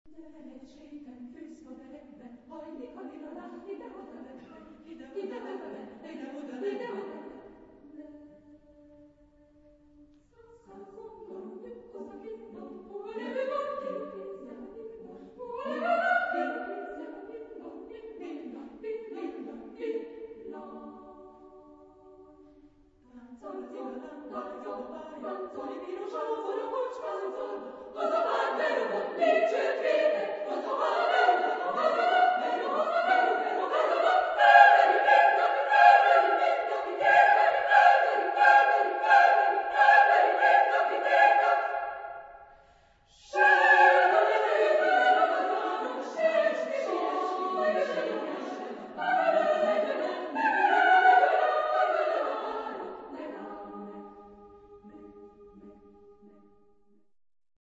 Genre-Style-Forme : Profane ; contemporain ; Chœur
Type de choeur : SMA  (3 voix égales de femmes )
Tonalité : tonal